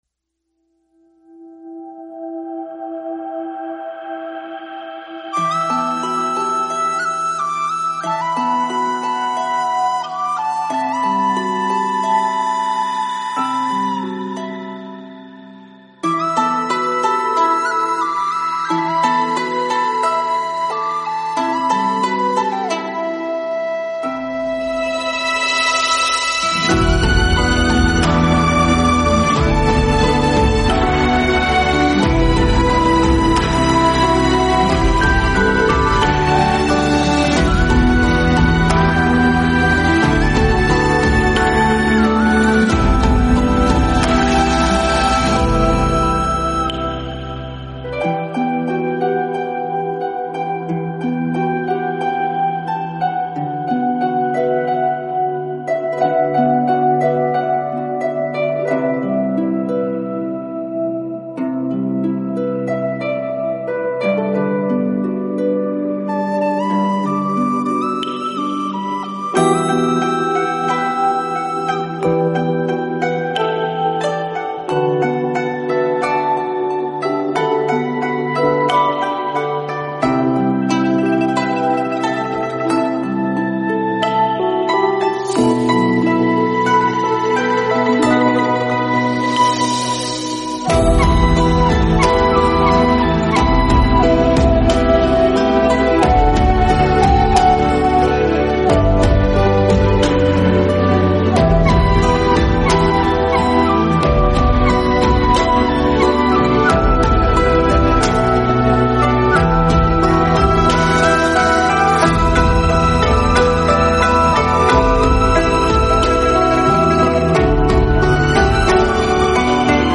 佛音 冥想 佛教音乐 返回列表 上一篇： 弄戏--刘珂矣 下一篇： 语烟